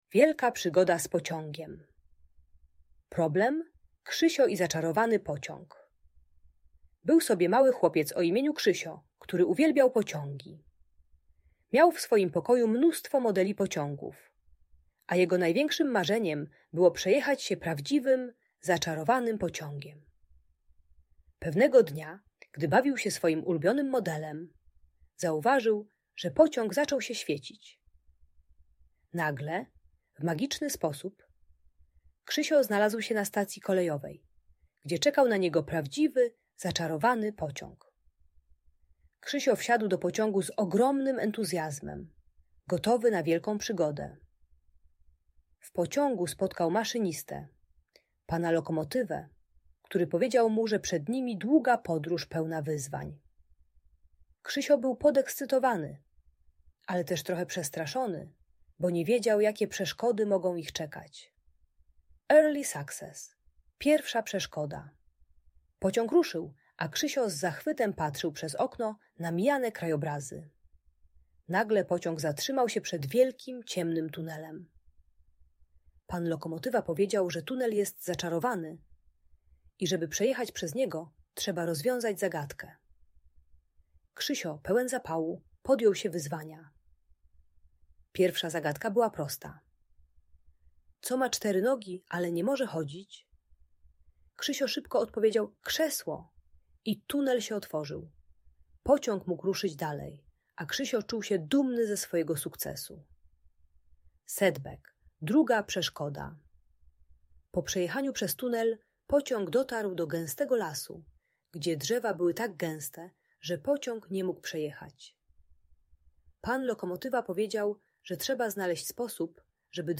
Wielka Przygoda z Pociągiem - Audiobajka